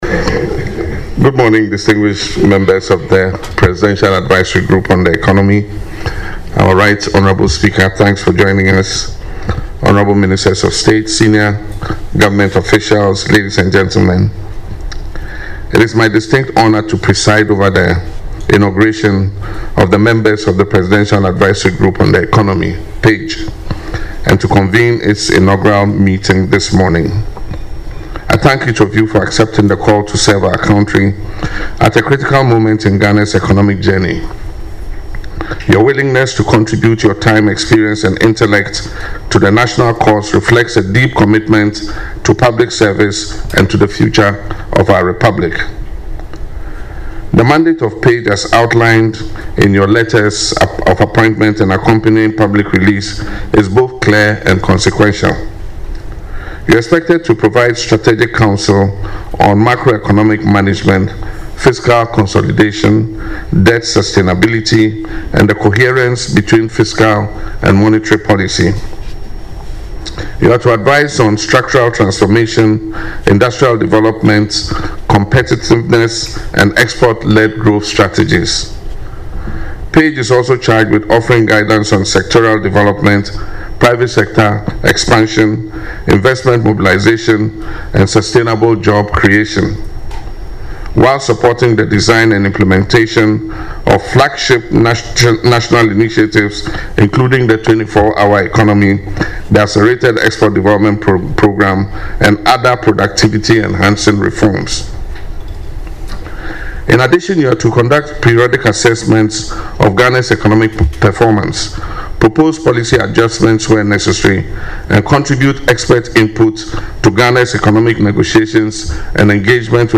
Addressing the members at the swearing-in ceremony, President Mahama expressed gratitude to the group for accepting the call to serve at what he described as a defining moment in Ghana’s economic journey.